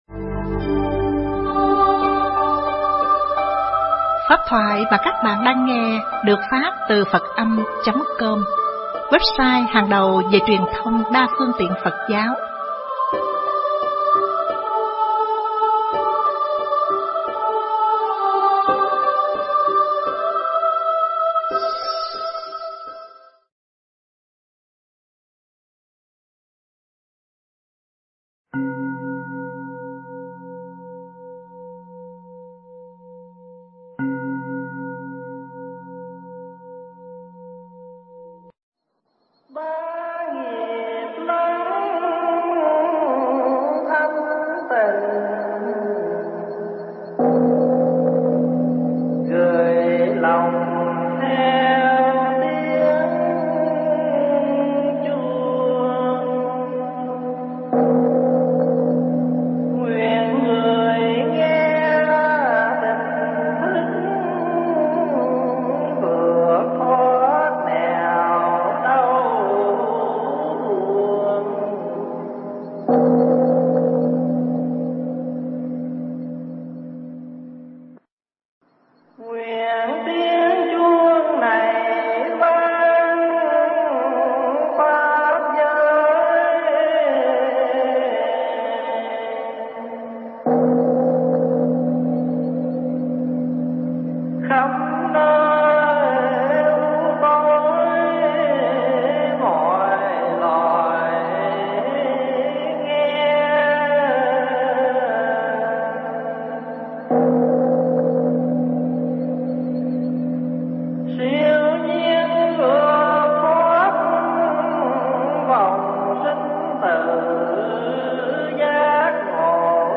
thuyết giảng tại Chùa Quán Thế Âm (Đan Mạch)